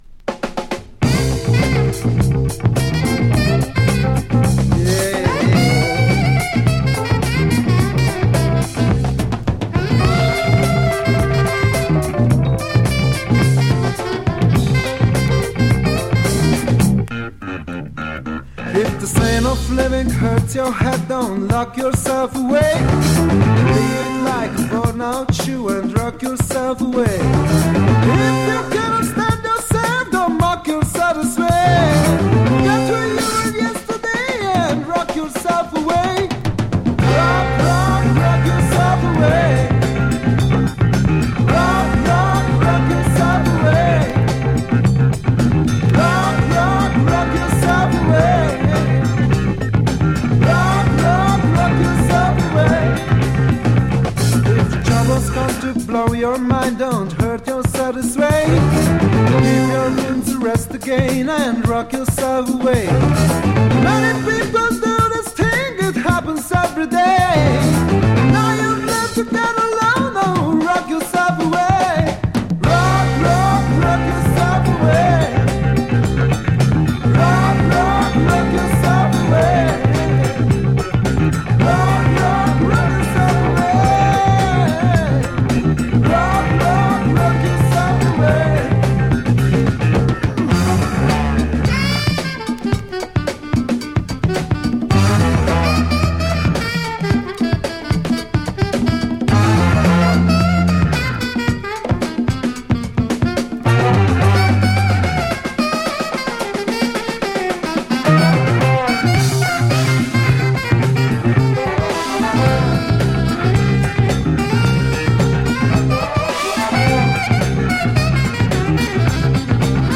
ハンガリーを代表するロックグループ
タイトなリズムでグイグイ引っ張るFUNKYロック